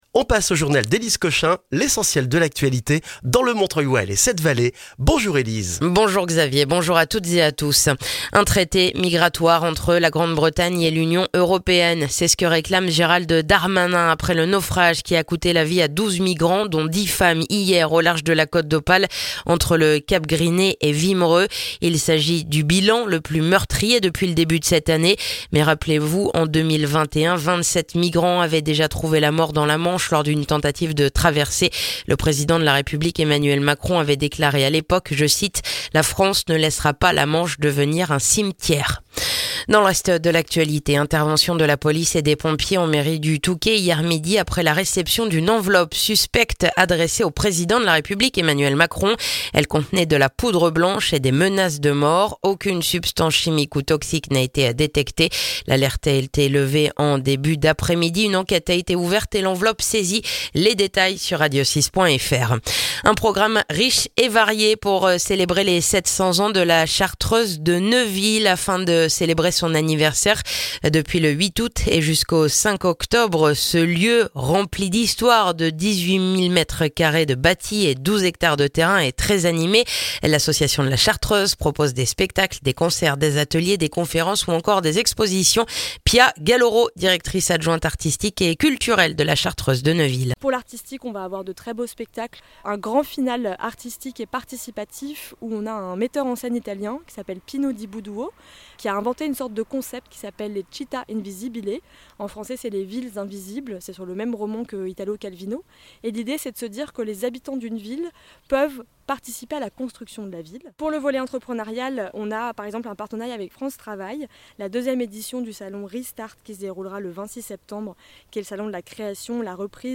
Le journal du mercredi 4 septembre dans le montreuillois et les 7 Vallées